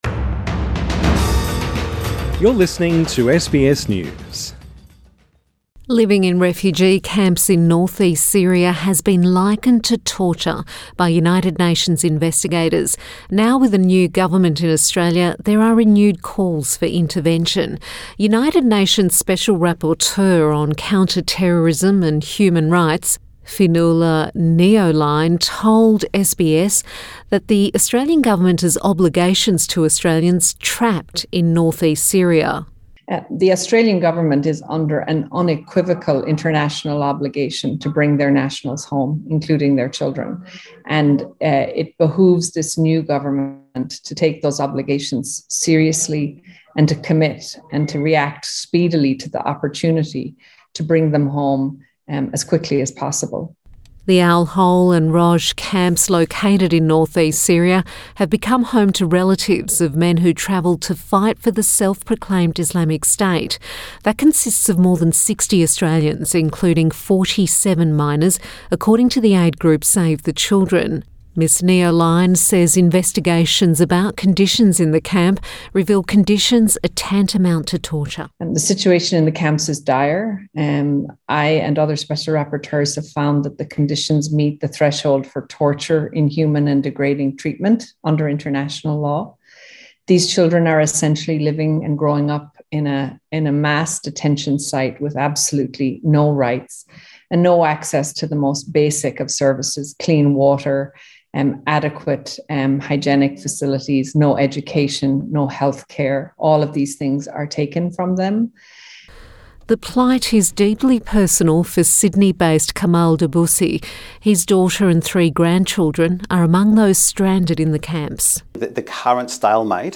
In an exclusive interview with SBS, the UN Special Rapporteur on counter-terrorism and human rights says she intends to meet with the Government in coming weeks - calling for action.